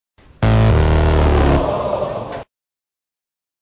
fail_old.ogg